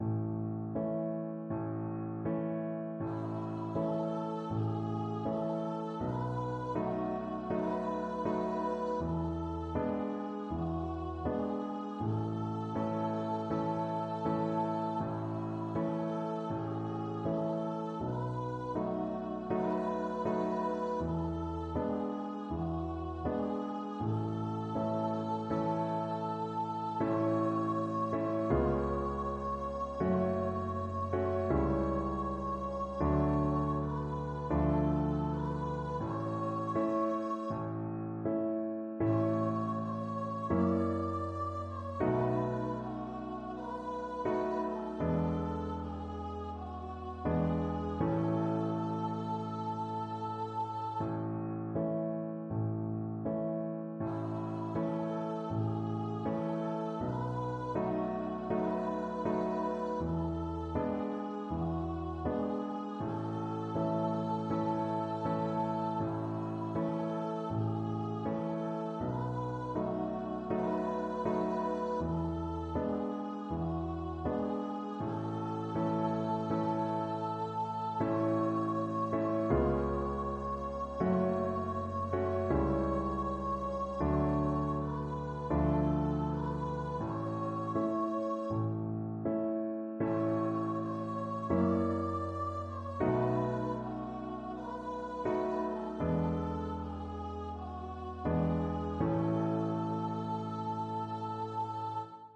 Andante = c. 80
4/4 (View more 4/4 Music)
E5-D6
Unison Choir  (View more Easy Unison Choir Music)
Traditional (View more Traditional Unison Choir Music)
Rock and pop (View more Rock and pop Unison Choir Music)